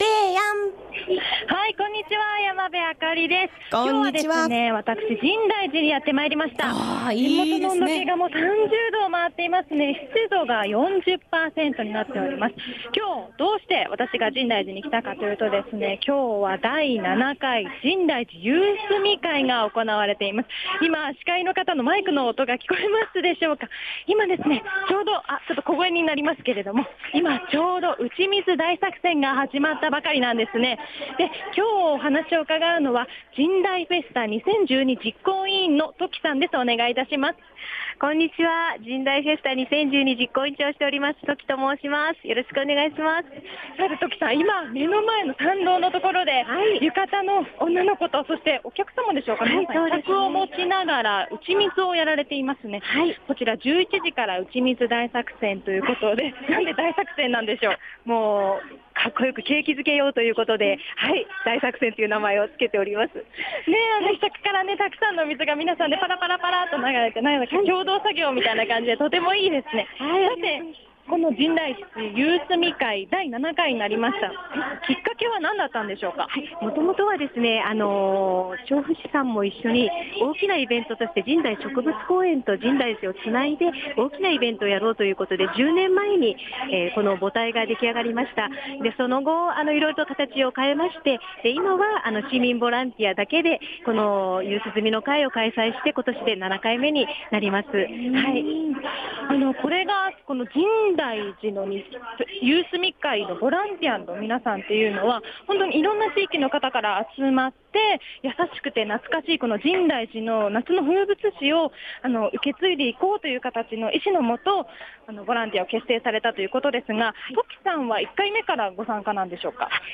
びゅーサン 街角レポート
今日は第７回深大寺夕涼み会が開催されていました！